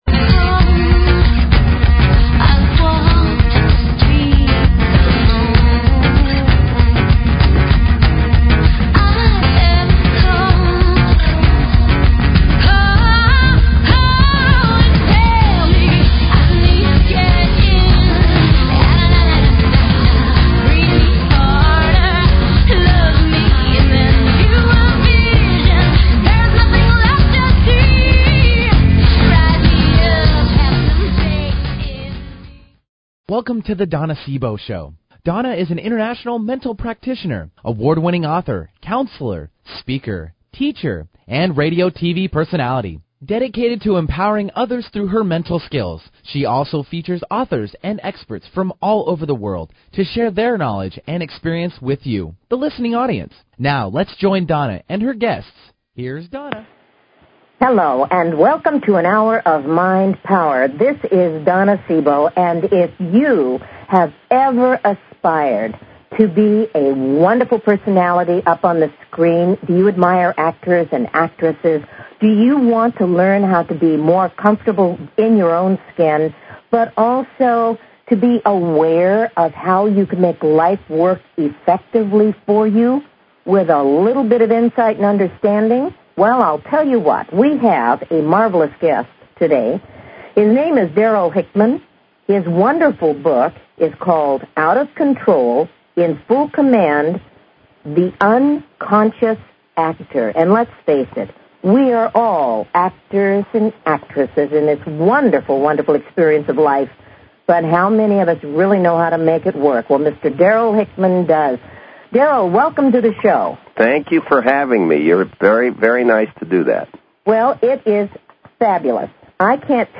Talk Show Episode
'The Unconscious Actor', Darryl Hickman. This will be a fascinating interview with a professional actor who turned teacher.
Callers are welcome to call in for a live on air psychic reading during the second half hour of each show.